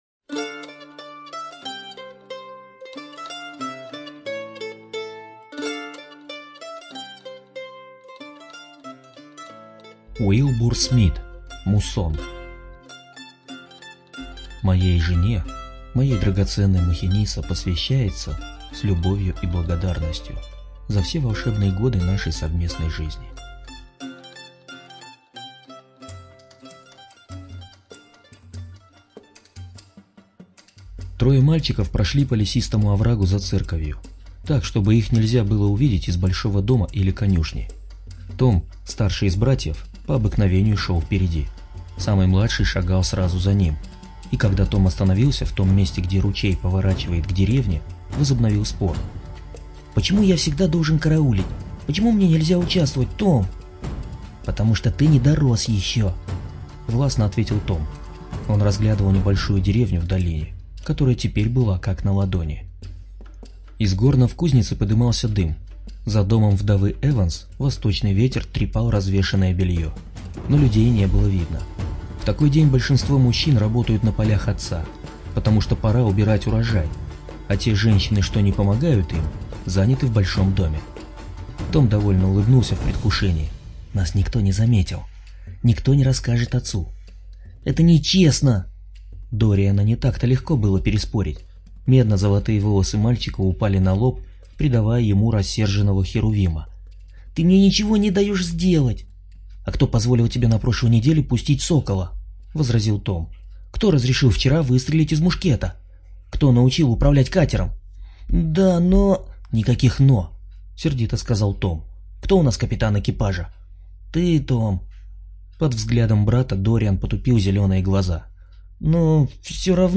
такую книгу и так испаганить страной музычкой